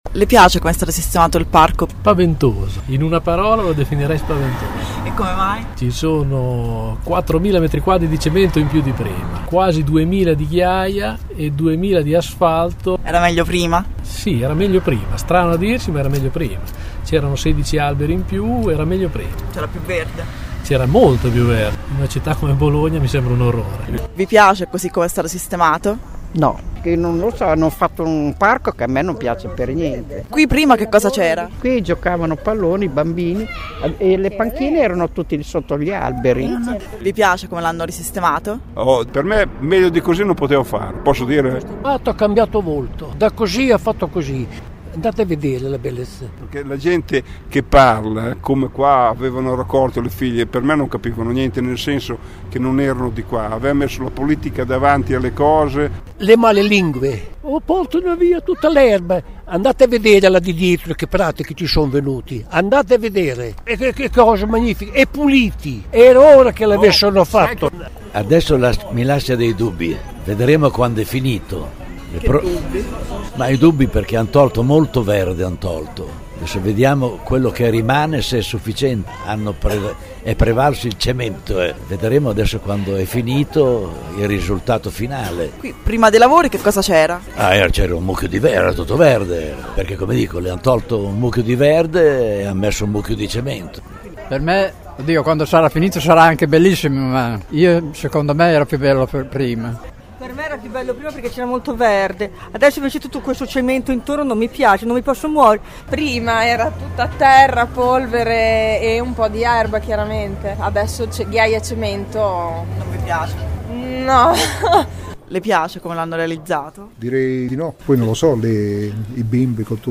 Ascolta le voci del quartiere